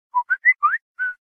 알림음 Samsung whistle
samsung_whistle.mp3